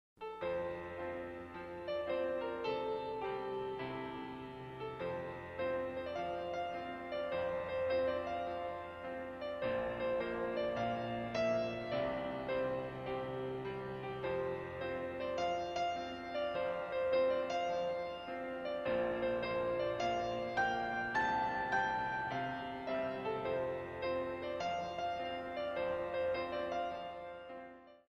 33 Piano Selections.